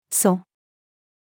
礎-female.mp3